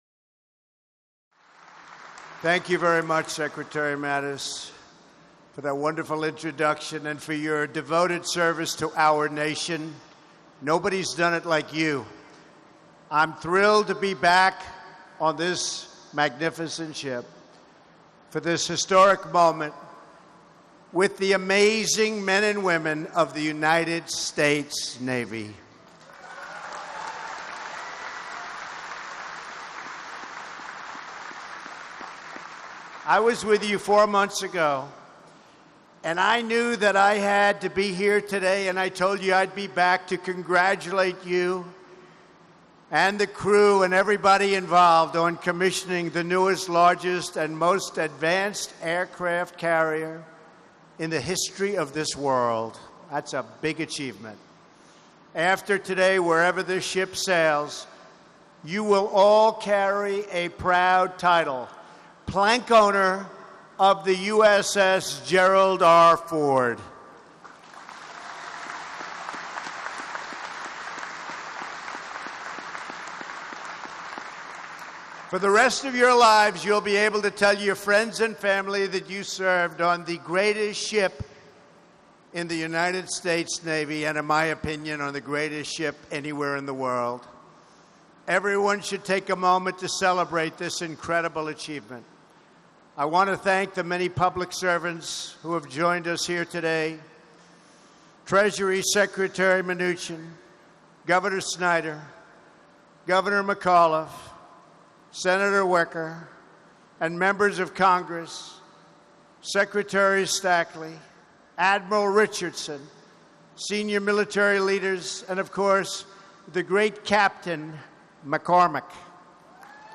Remarks by President Trump at Commissioning Ceremony for the USS Gerald R. Ford (CVN-78)